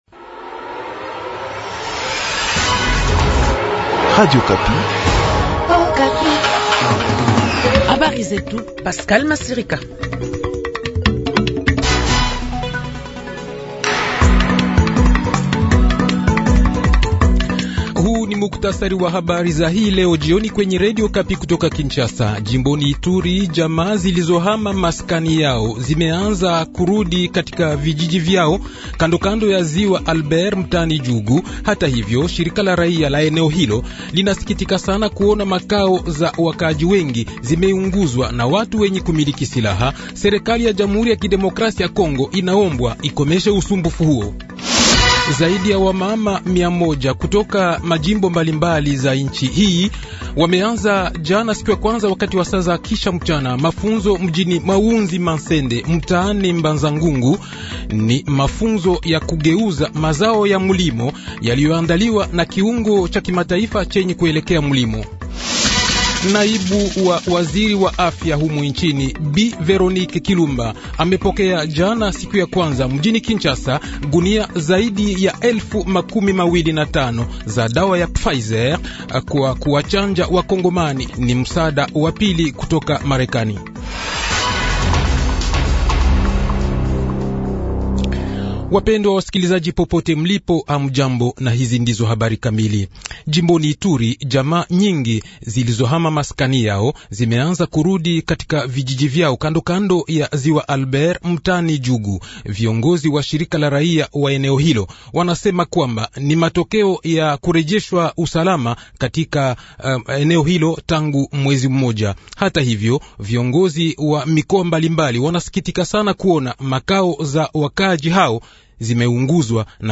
Le journal Swahili de 17 h, 28 septembre 2021